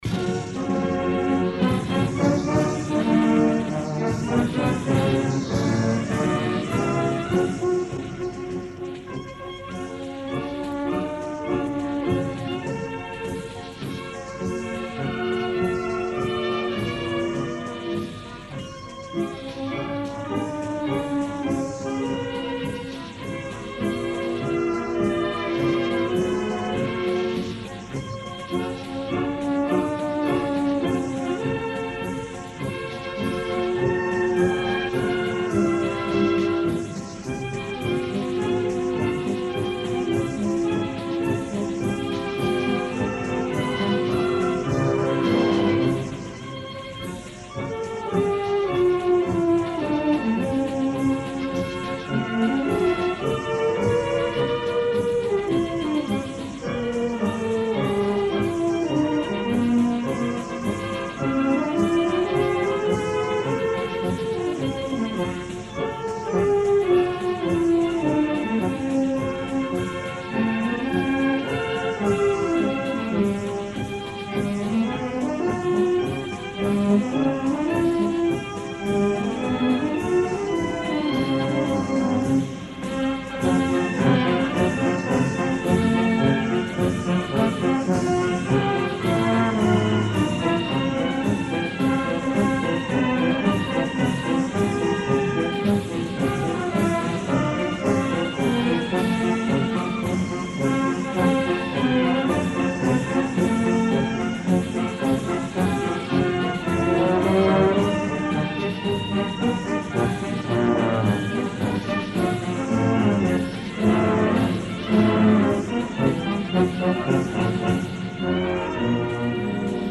Concert de Santa Cecília a l'Esglèsia de la Nostra Senyora de la Consolació